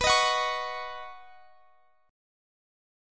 Listen to Badd9 strummed